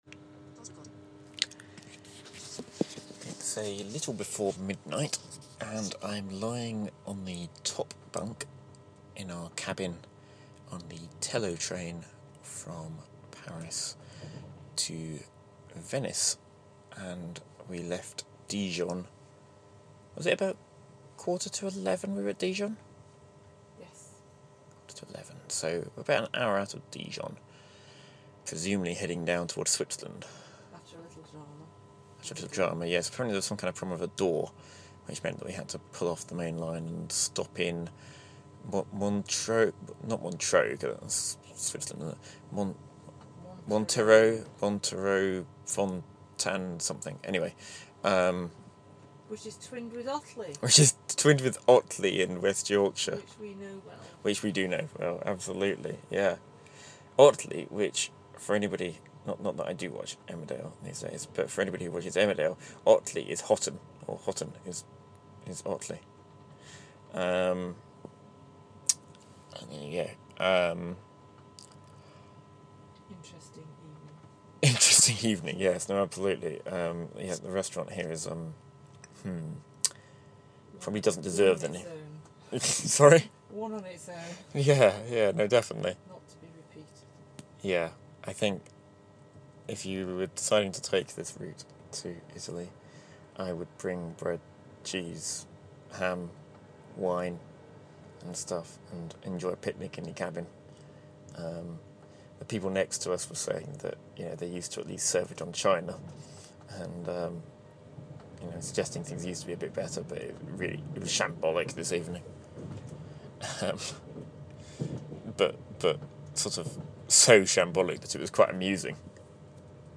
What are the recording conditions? Venice weekend: Reflections in the dark aboard the Thello sleeper